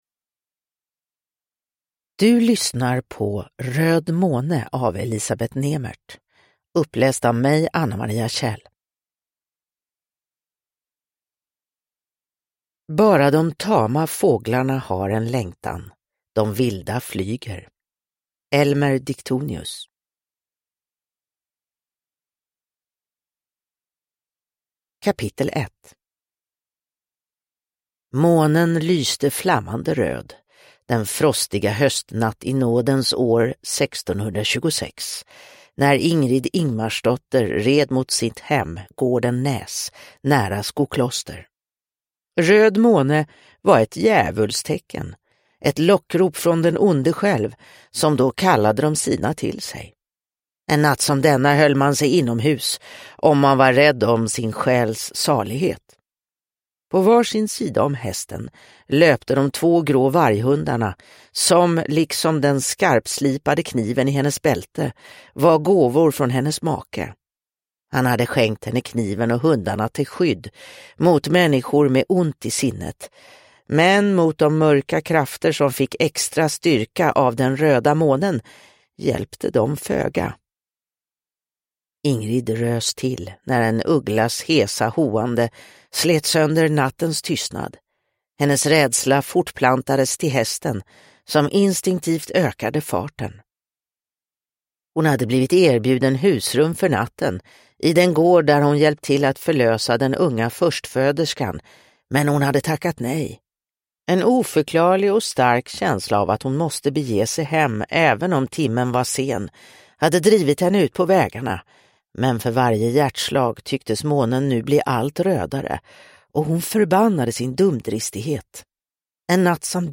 Röd måne – Ljudbok – Laddas ner